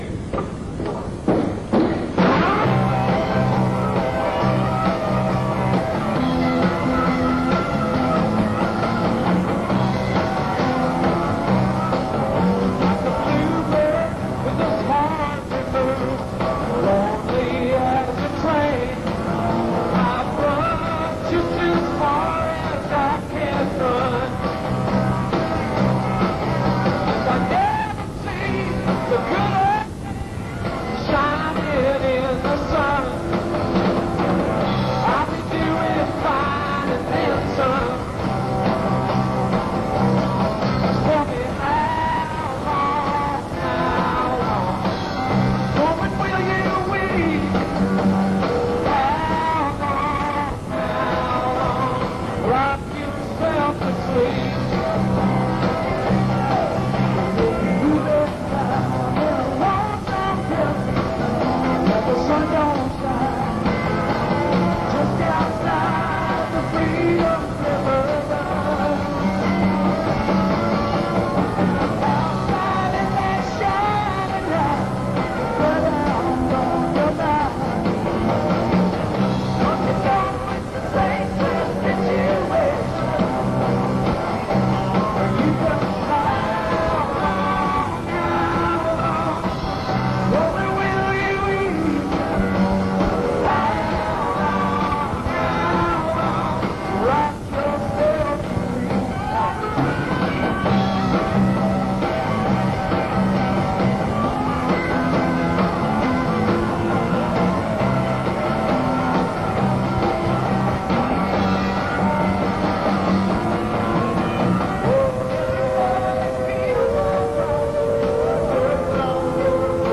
Royal Festival Hall, London